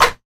Snare (43).wav